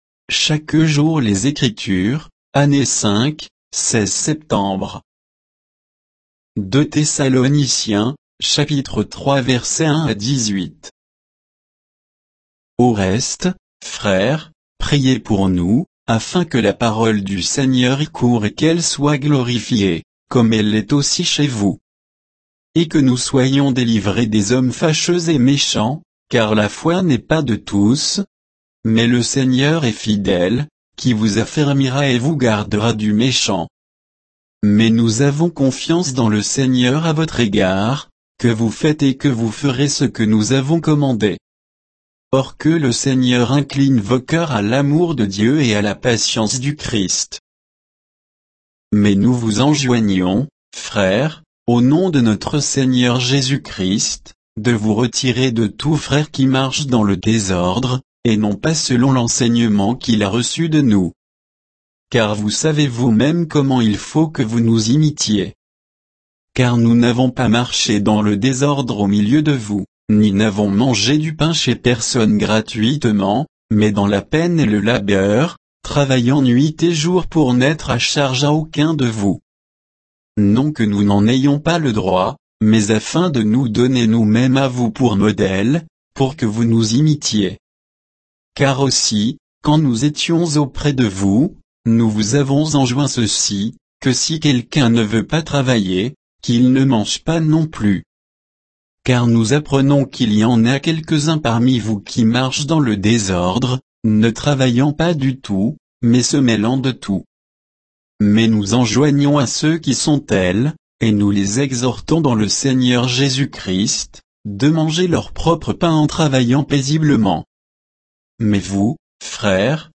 Méditation quoditienne de Chaque jour les Écritures sur 2 Thessaloniciens 3, 1 à 18